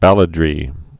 (bălə-drē)